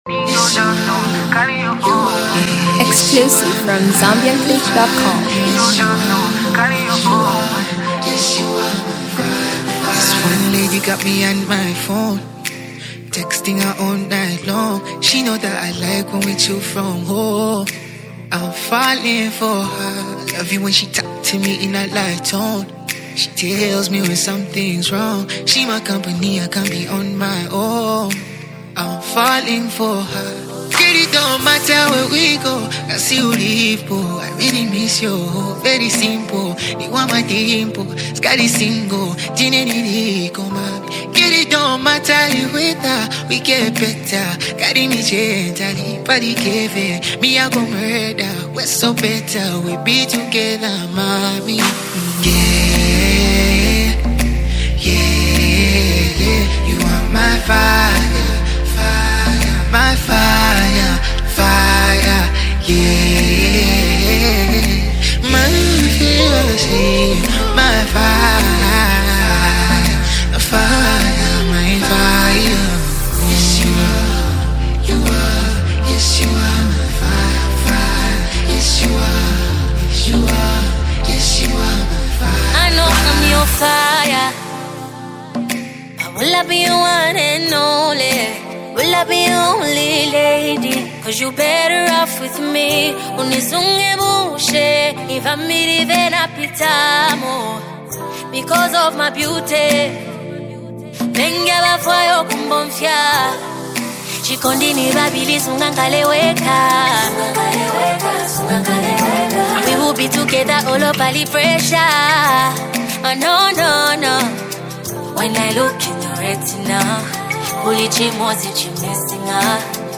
female best vocalist